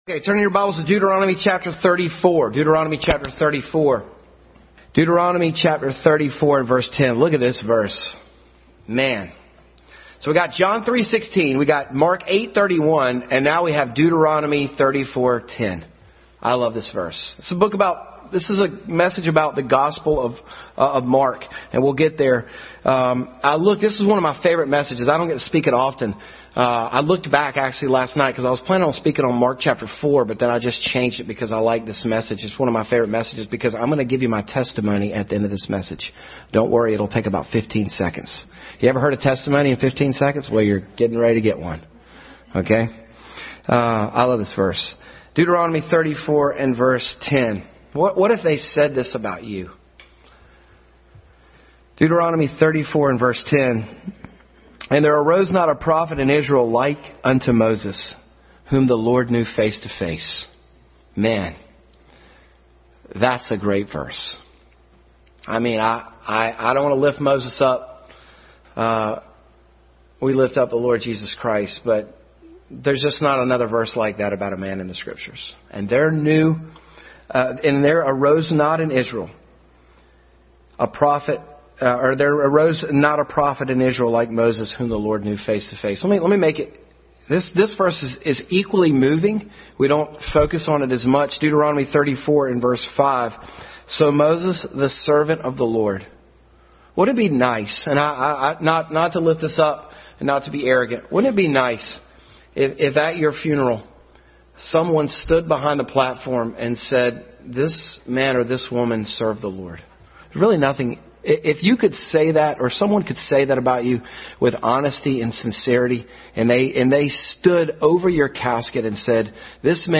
Family Bible Hour Message.
9:1-4 Service Type: Family Bible Hour Family Bible Hour Message.